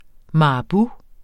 Udtale [ mɑɑˈbu ]